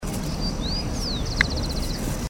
Tico-tico (Zonotrichia capensis)
Nome em Inglês: Rufous-collared Sparrow
Fase da vida: Adulto
Localidade ou área protegida: Reserva Ecológica Costanera Sur (RECS)
Condição: Selvagem
Certeza: Gravado Vocal